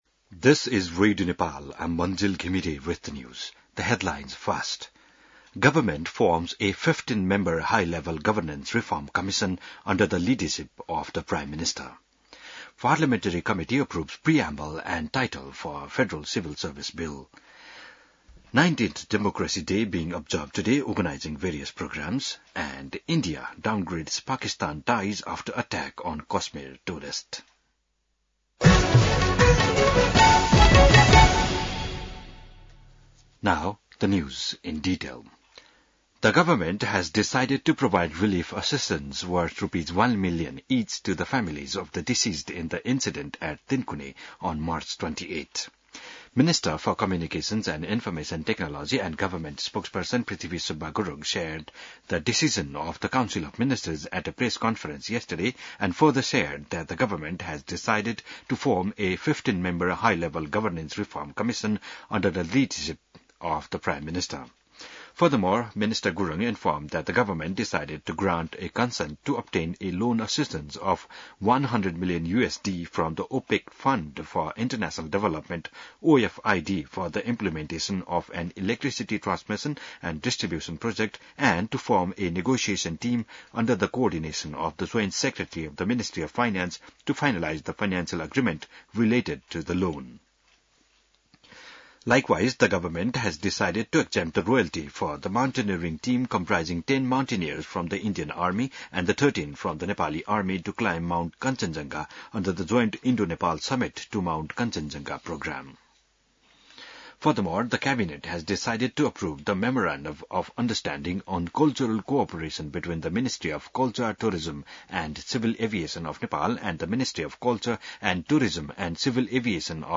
बिहान ८ बजेको अङ्ग्रेजी समाचार : ११ वैशाख , २०८२